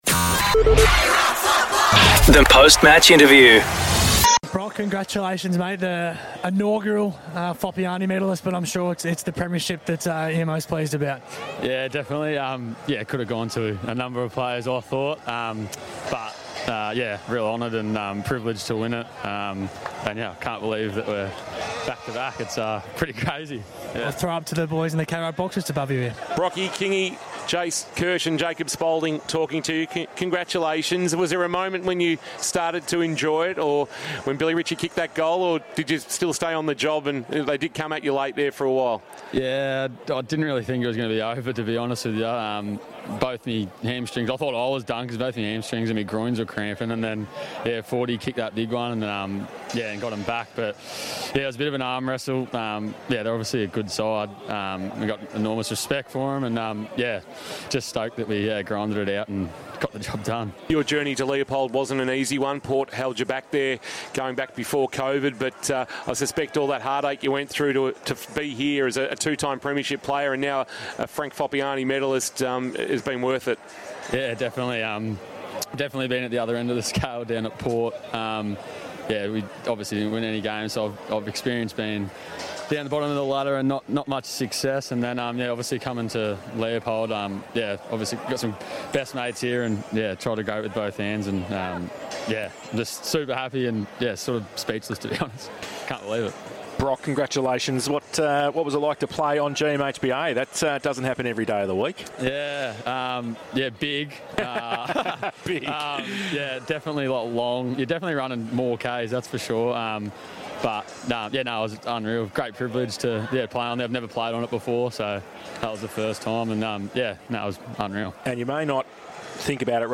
2024 - GFNL - Grand Final - Leopold vs. South Barwon: Post-match interview